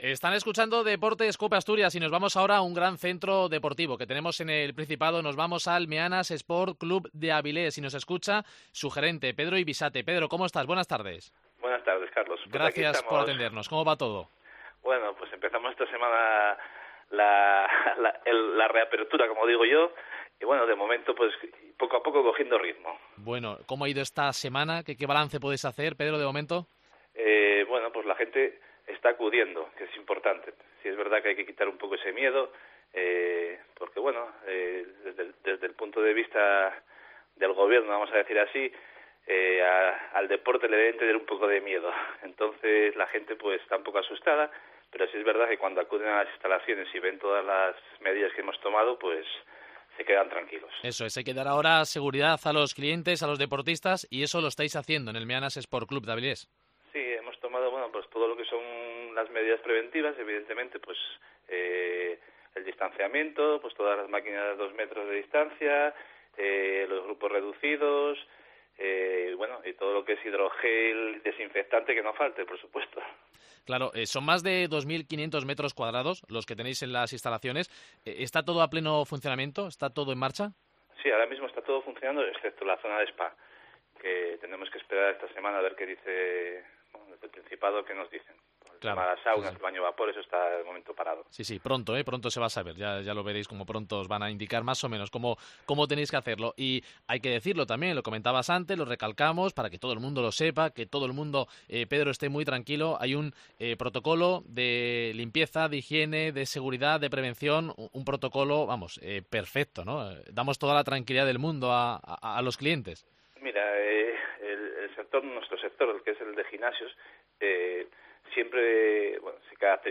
nos cuenta todas las novedades y las promociones especiales en una entrevista en Deportes COPE Asturias.